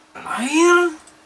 aeeeeeeeeeeerrrr.mp3